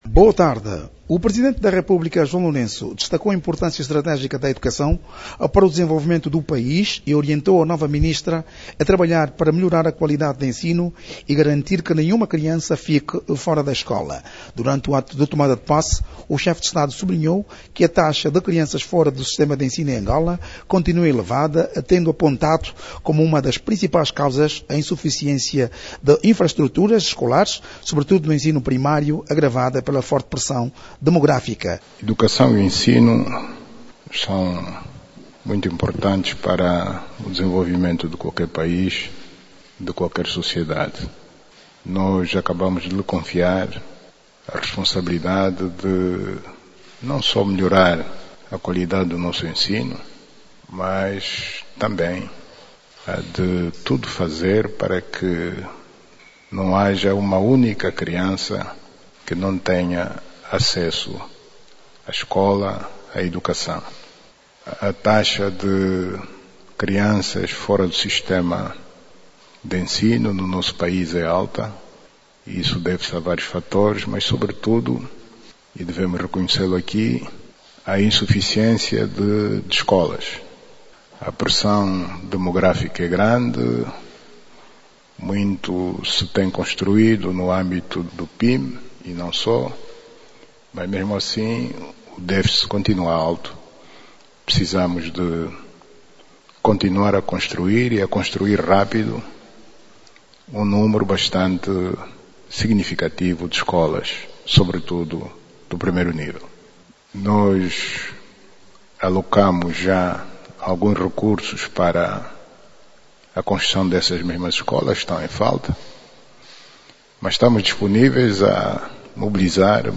As orientações foram transmitidas durante a cerimónia de posse da governante, realizada hoje, no salão nobre do Palácio Presidencial.